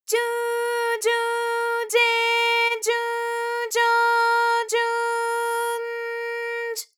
ALYS-DB-001-JPN - First Japanese UTAU vocal library of ALYS.
ju_ju_je_ju_jo_ju_n_j.wav